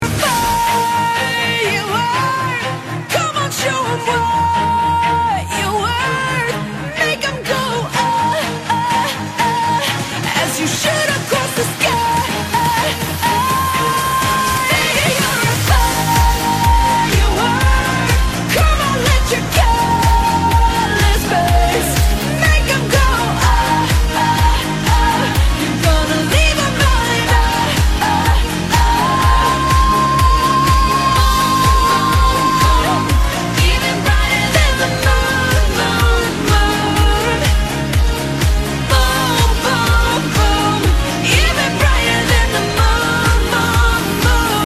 Firework Sound Effect Free Download
Firework